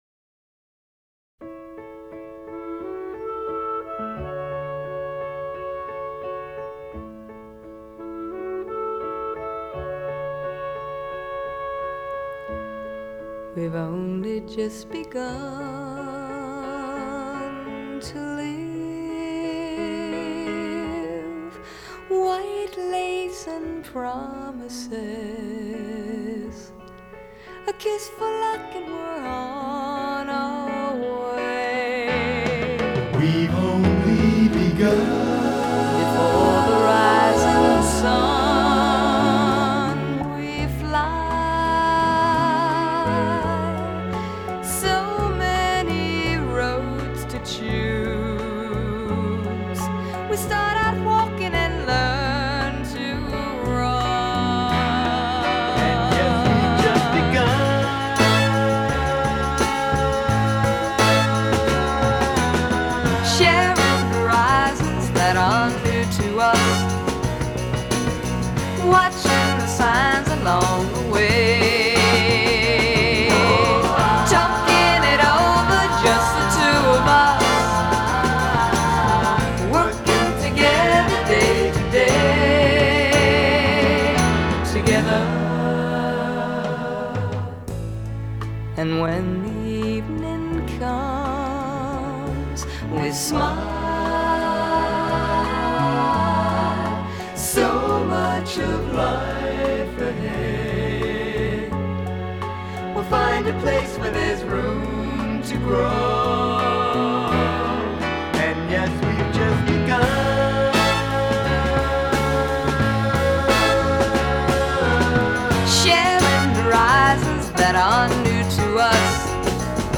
американский поп-дуэт
вокал и барабаны
фортепиано
Уверенный, похожий на колокольчик голос